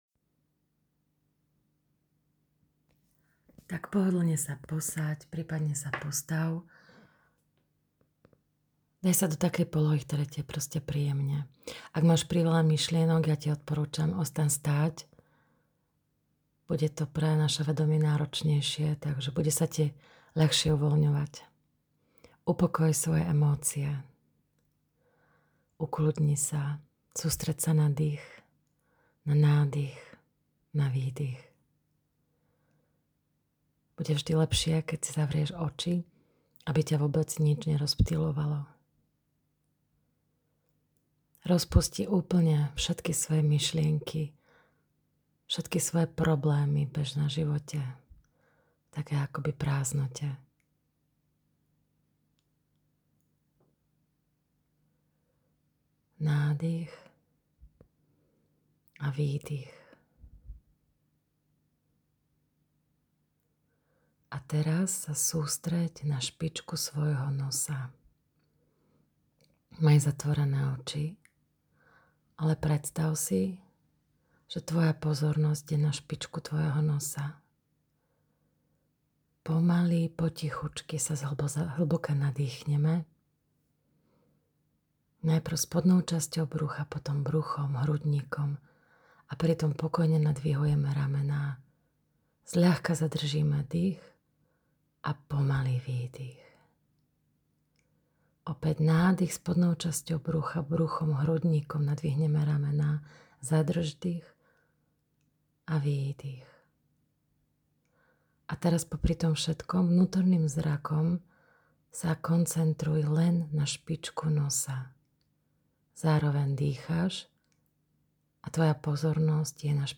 Koncentrácia na špičke nosa (5 min meditácia) Vypočuj alebo stiahni si audio Mini úloha po pozretí videa: Vyskúšaj si túto techniku ešte raz večer pred spaním.
Koncentracia-na-spicke-nosa.mp3